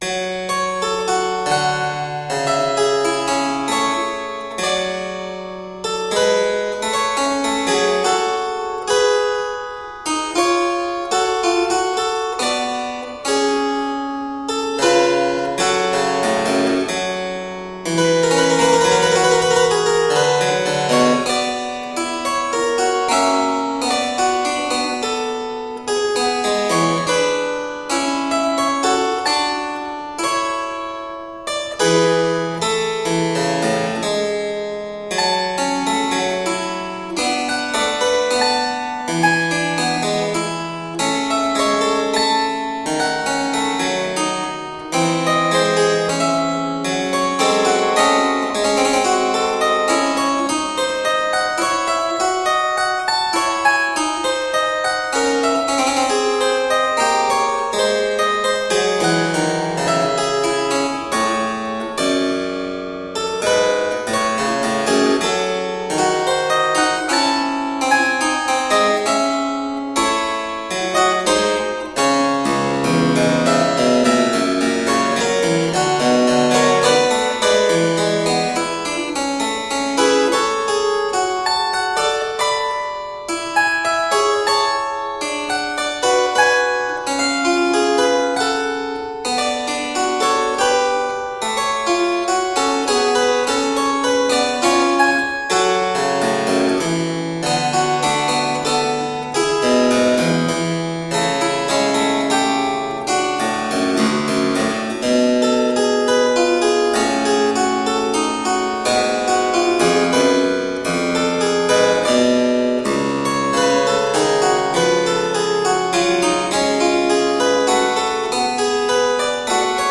Classical, Baroque, Instrumental
Harpsichord